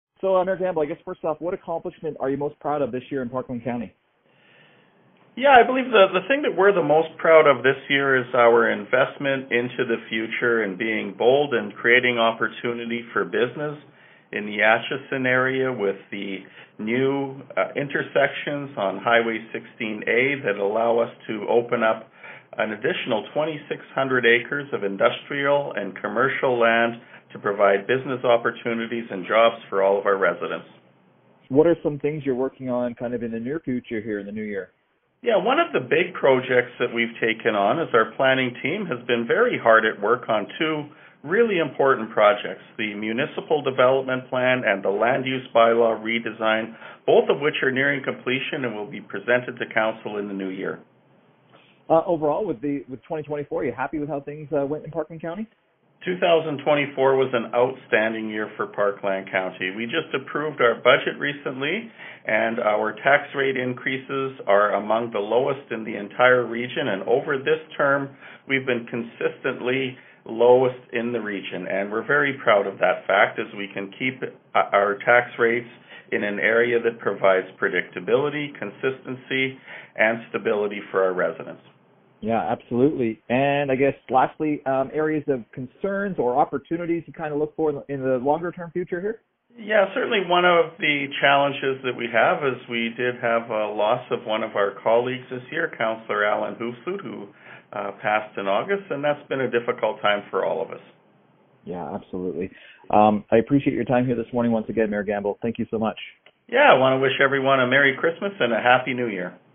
AB Parkland County mayor Allan Gamble shared his thoughts in his year end interview with The One.